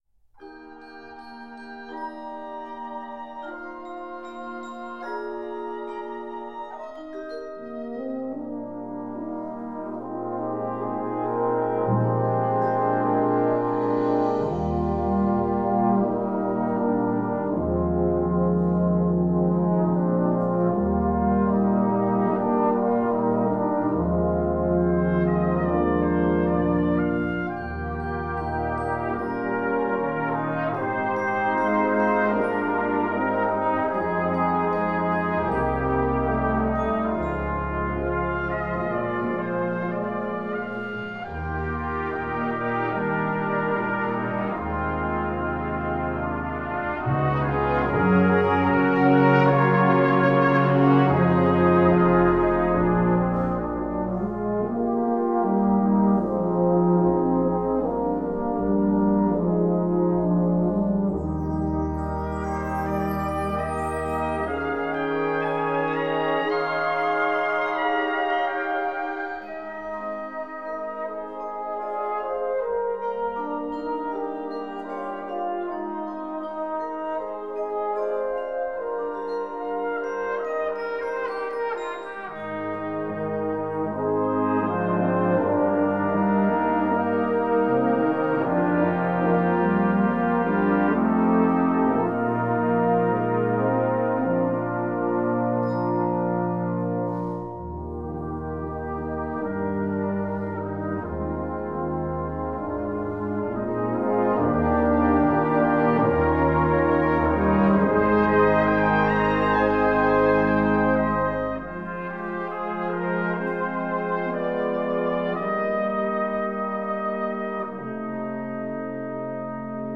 Christmas Music / Musique de Noël